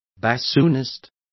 Complete with pronunciation of the translation of bassoonists.